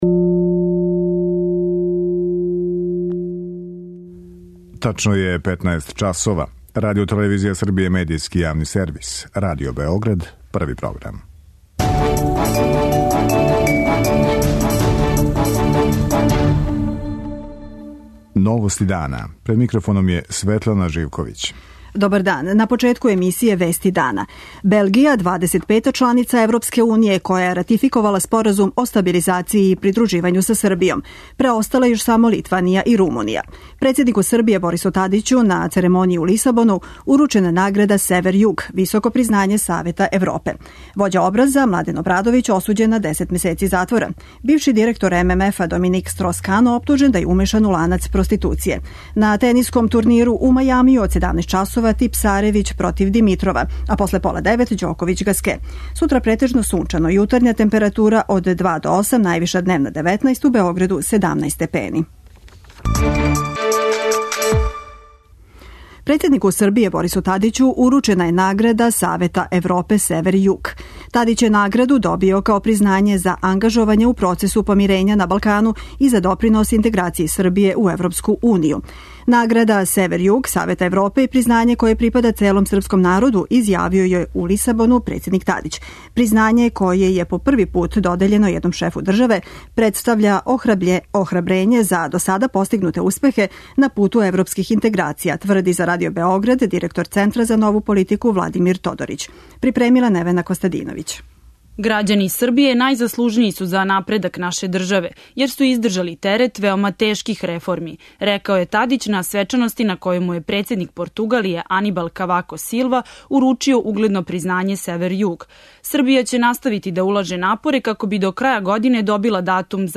У емисији ћете чути како су три различите стране оцењивале 27. март: краљ Петар, Хитлер и Јосип Броз Тито.
преузми : 15.77 MB Новости дана Autor: Радио Београд 1 “Новости дана”, централна информативна емисија Првог програма Радио Београда емитује се од јесени 1958. године.